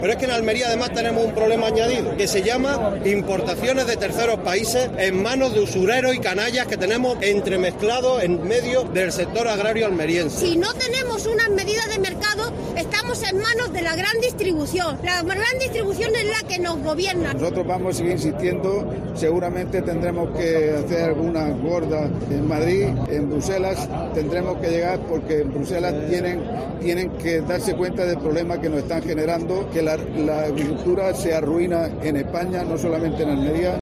AUDIO: Los representantes de las organizaciones agrarias hablan en la manifestación convocada en El Ejido.